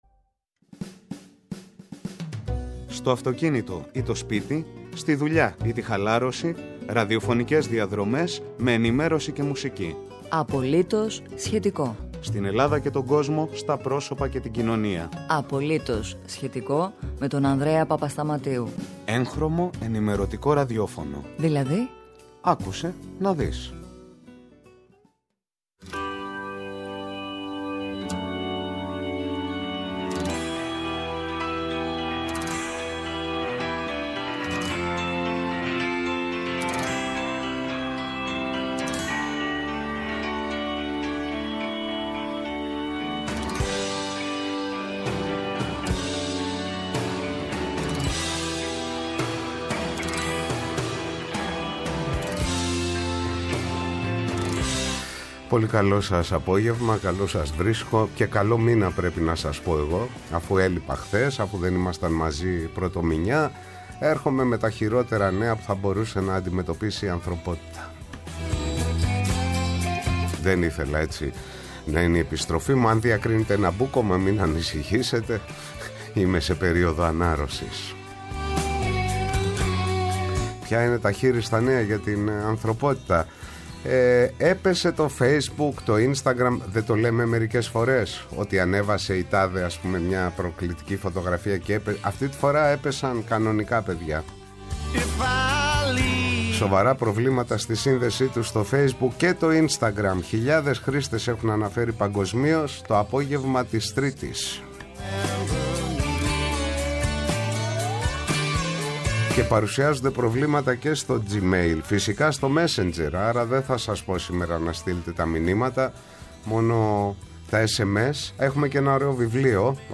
Καλεσμένος απόψε ο εργατολόγος και καθηγητής του Εργατικού Δικαίου Αλέξης Μητρόπουλος.